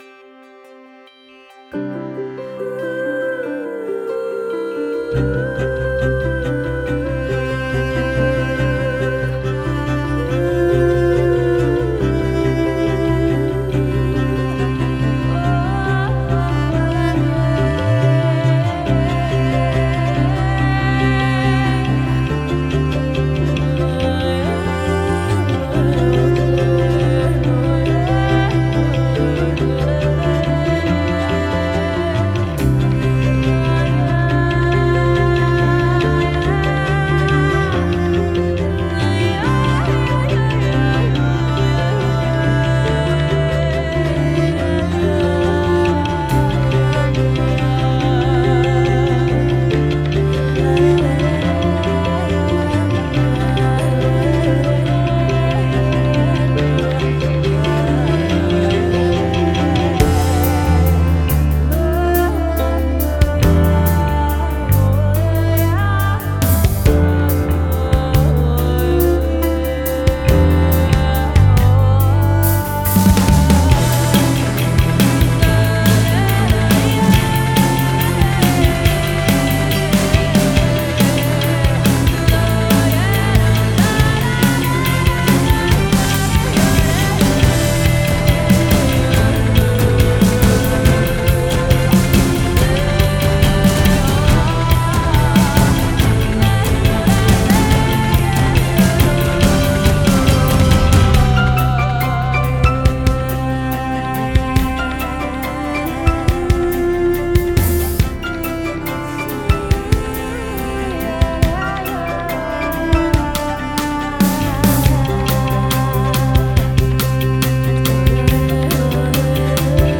synth
вокал
bass
duduk